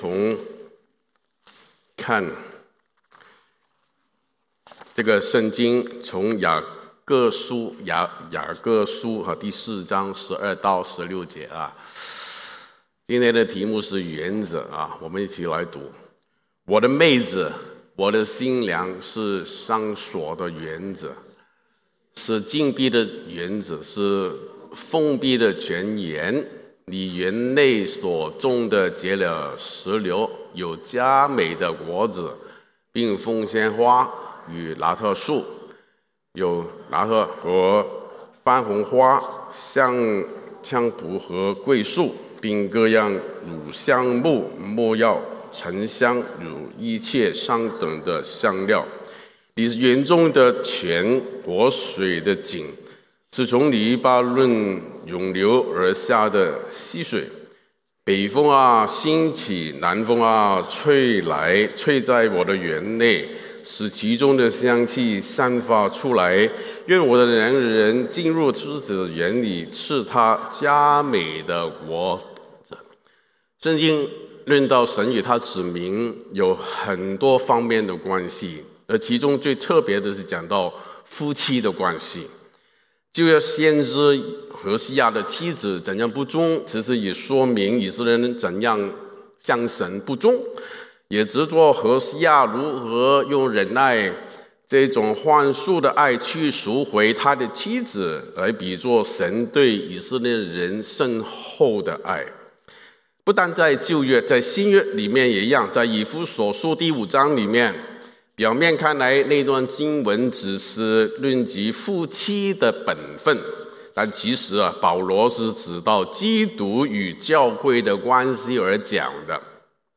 Sermon – 第 22 頁 – 澳亞基督教會 Austral-Asian Christian Church